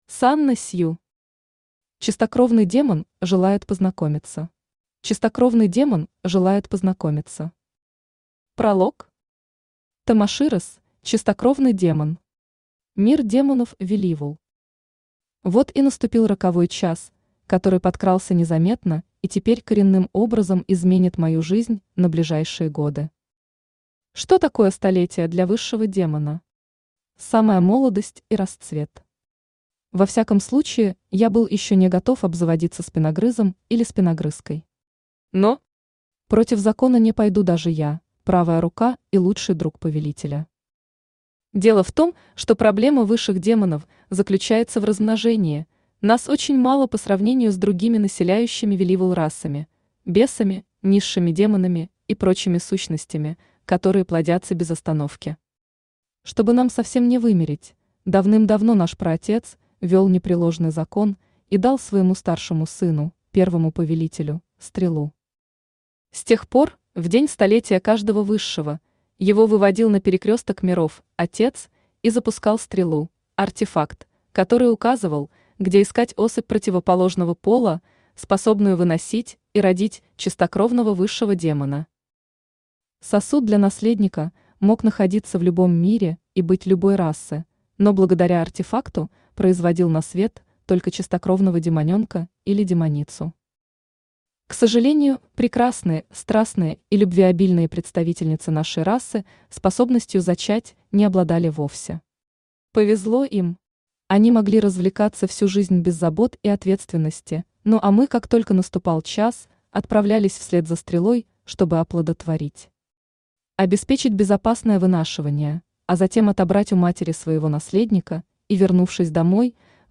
Аудиокнига Чистокровный демон желает познакомиться | Библиотека аудиокниг